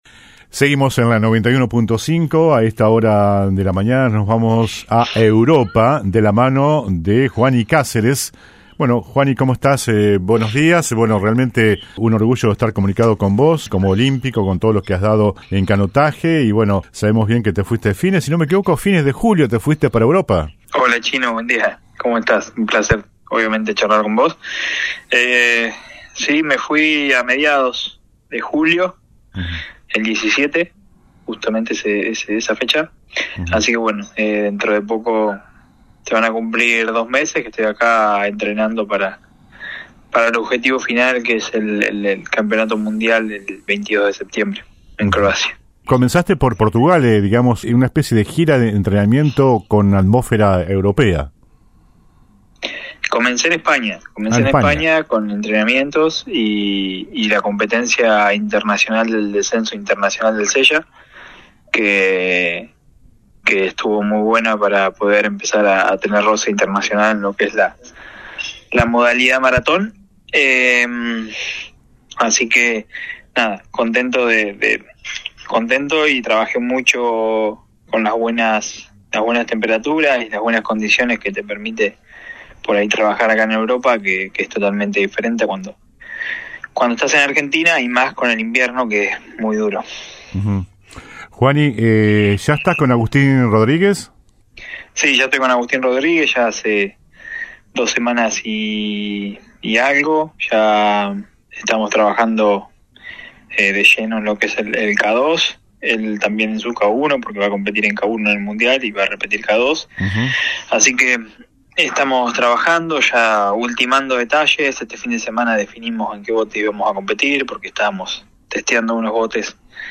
AUDIO COMPLETO DE LA ENTREVISTA